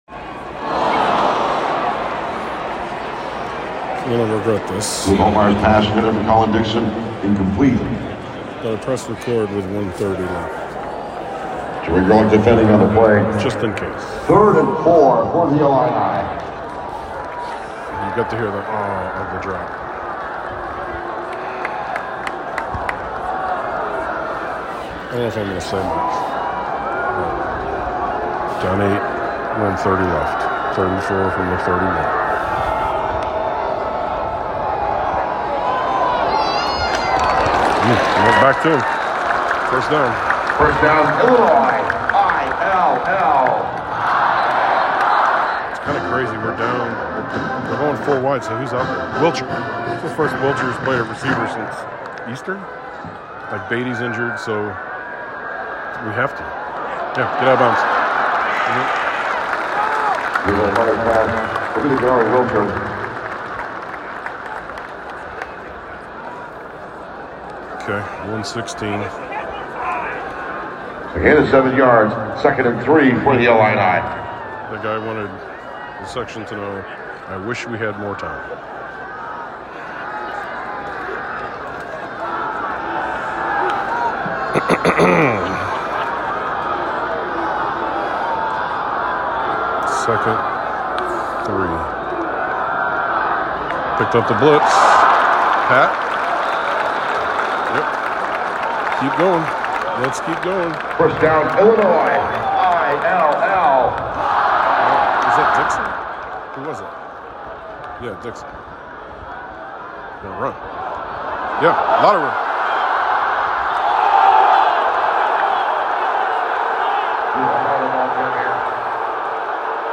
I don't know why, but I started recording during our final drive attempting to tie the game. And then I just kept recording my thoughts immediately after the game.